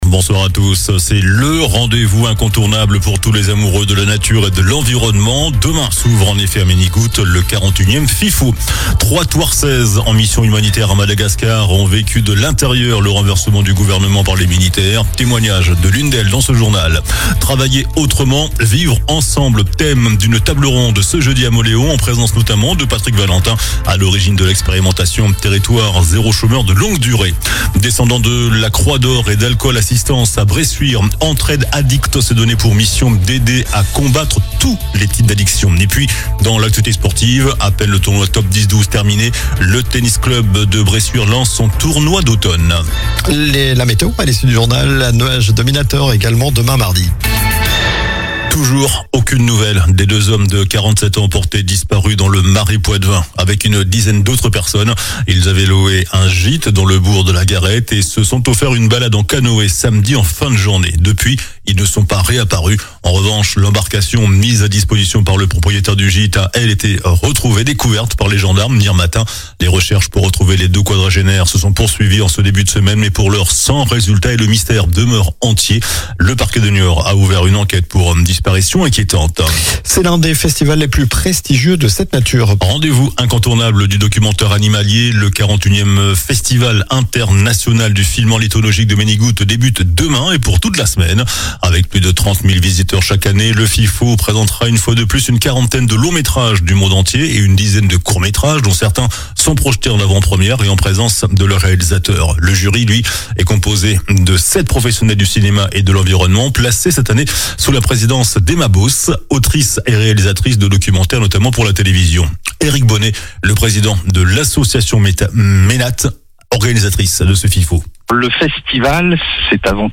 JOURNAL DU LUNDI 27 OCTOBRE ( SOIR )